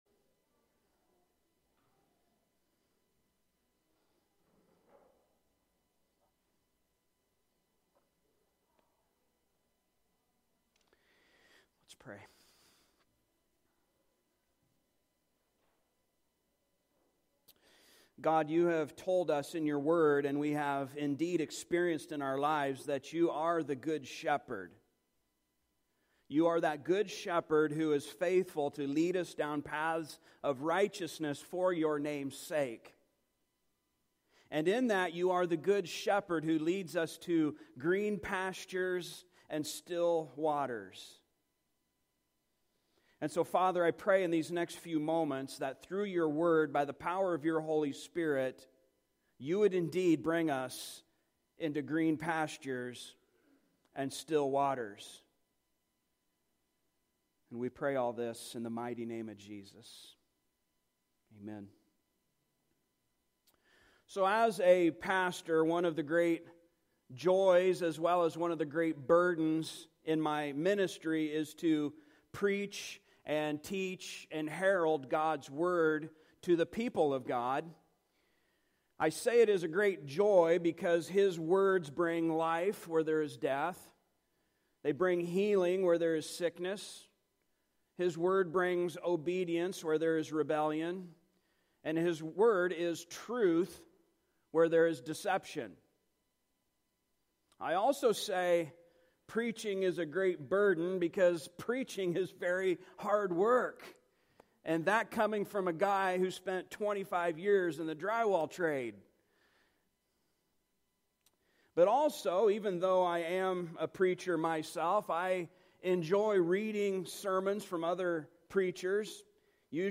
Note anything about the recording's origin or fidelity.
Service Type: Sunday Morning Topics: Christian Life , Law , Love